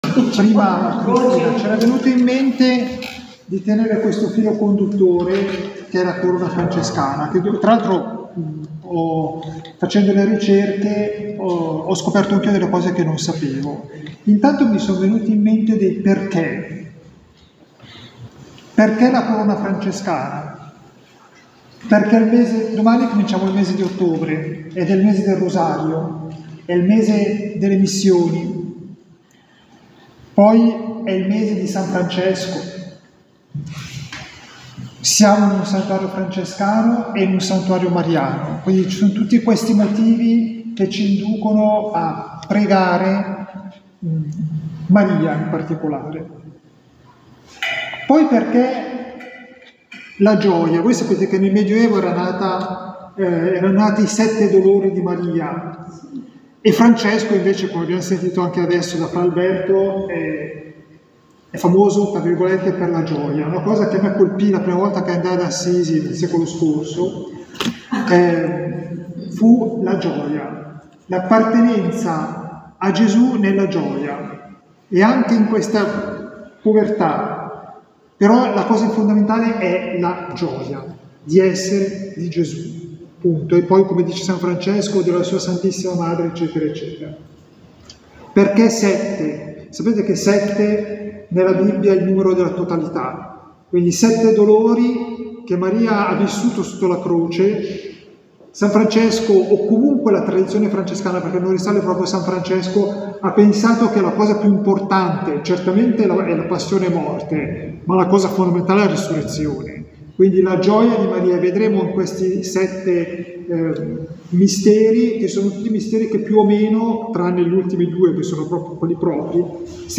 Durante questo fine-settimana, da sabato 30 settembre a domenica 01 ottobre, si è svolto alle Grazie, il ritiro per formandi e neo-professi della nostra fraternità dal tema " LETIZIA .... PERFETTA LETIZIA ".